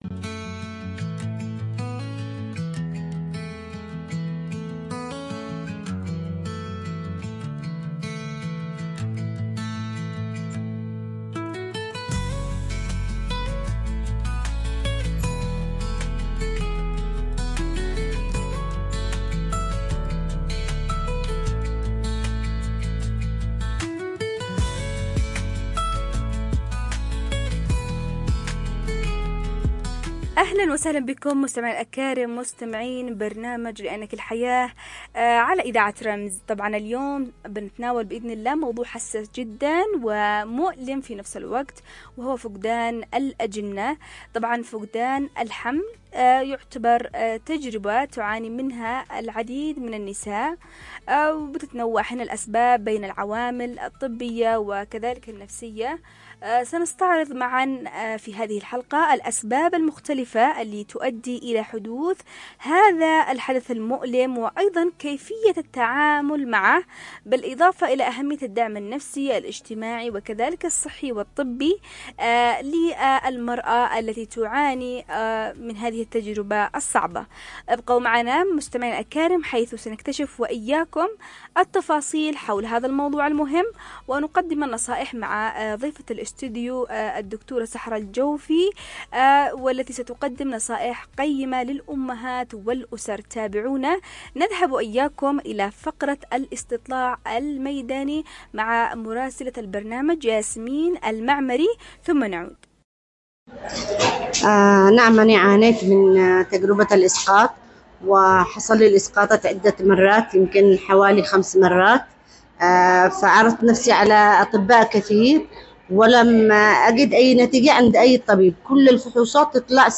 في حوار مؤثر حول هذه القضية الحساسة التي تمس حياة الكثير من النساء. 📅 الموعد: يوم الأربعاء ⏰ الساعة: 01:00 ظهرًا 📻 عبر أثير: إذاعة رمز لا تفوّتوا هذه الحلقة الخاصة التي تفتح باب الوعي والدعم لكل امرأة مرت أو تمر بتجربة فقدان الحمل.